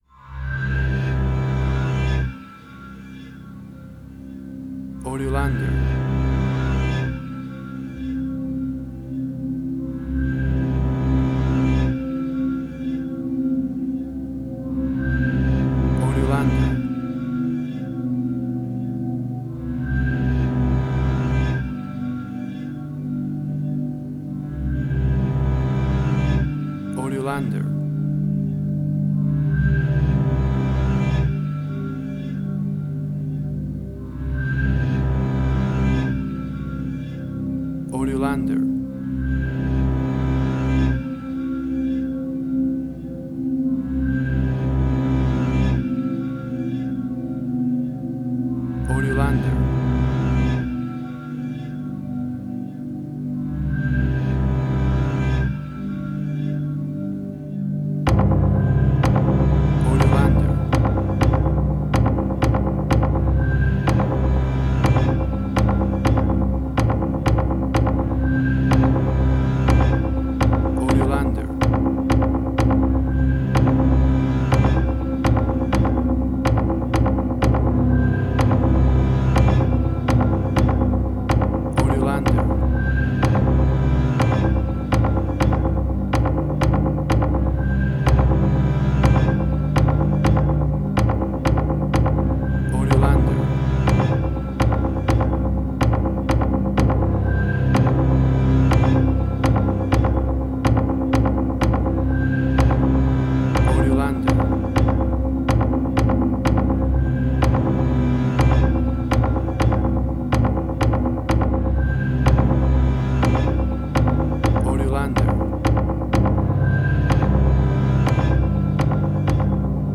Post-Electronic.
Tempo (BPM): 124